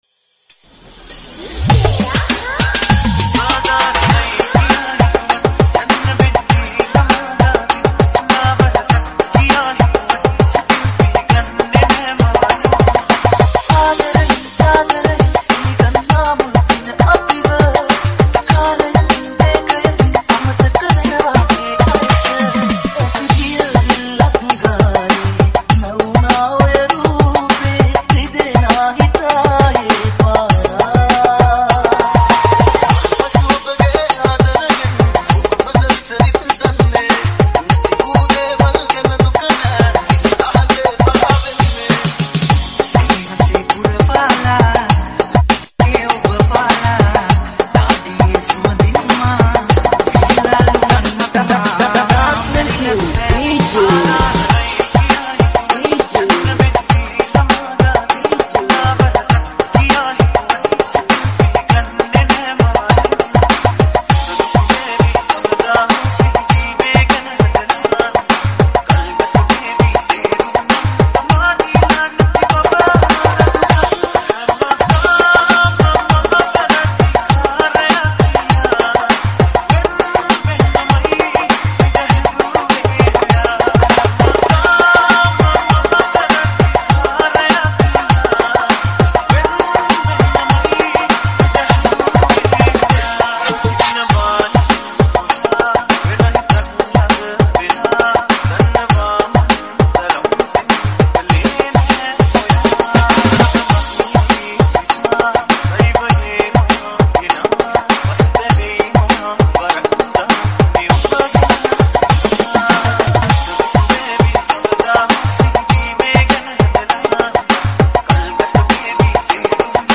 High quality Sri Lankan remix MP3 (5.9).